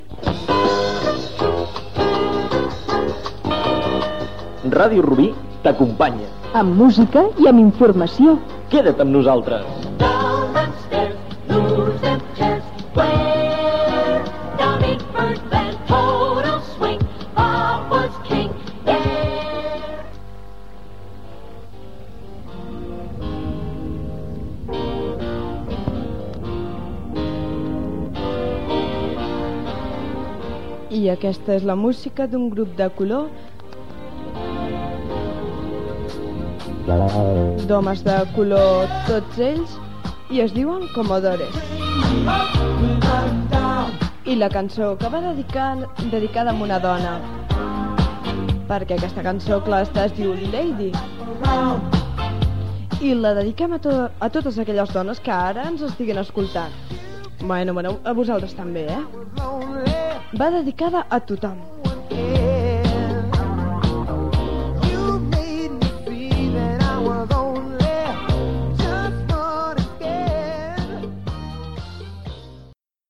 3f2463dbd8ca4e0f30f2e3af3d2321b9d6e15a26.mp3 Títol Ràdio Rubí Emissora Ràdio Rubí Titularitat Pública municipal Descripció Presentació tema musical.